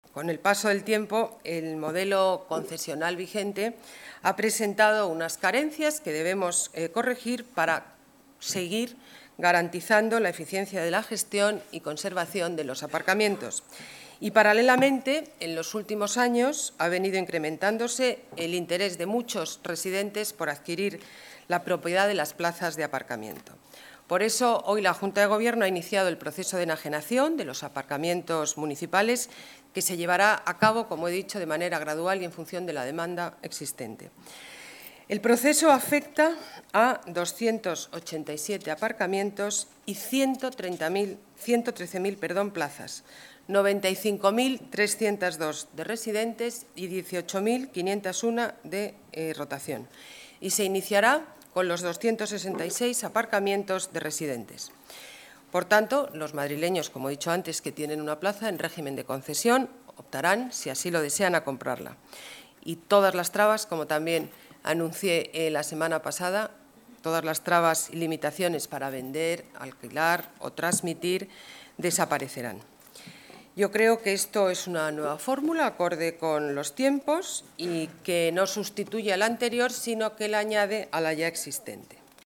Nueva ventana:Declaraciones de la alcaldesa